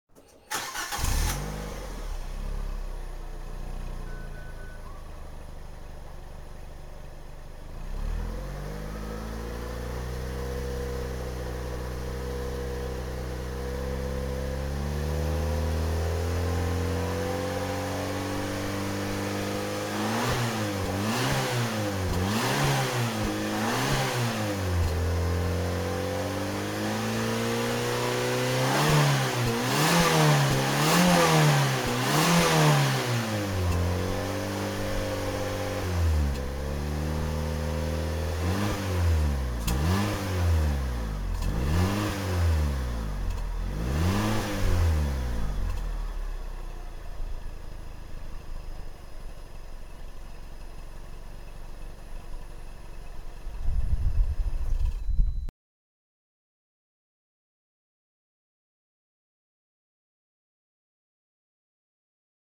ノーマルマフラー音 | PiccoloCars /ピッコロカーズの公式サイト
フィアット500 1.2のノーマルマフラー音です。